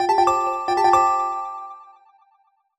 jingle_chime_06_positive.wav